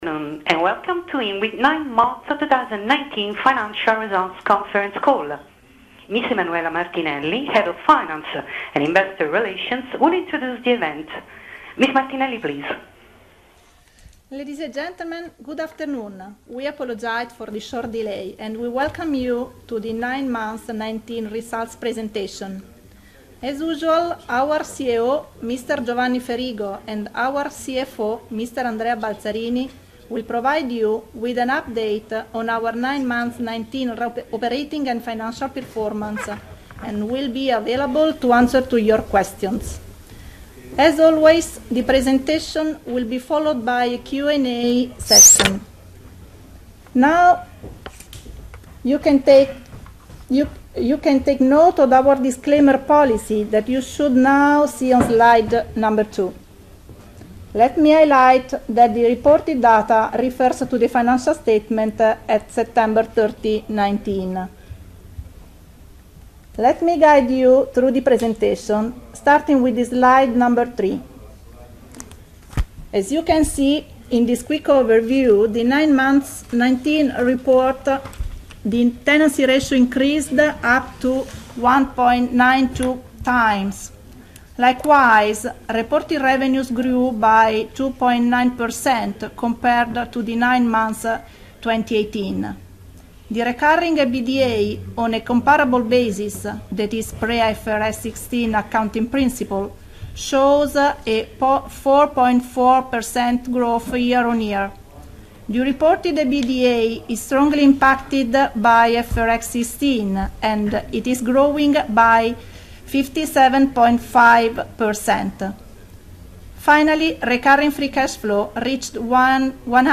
Download Investor Presentation Download Financial Data (EXCEL) Read the Press Release about 9M’19 Financial Results Download the attachments to the Press Release Listen and download the conference call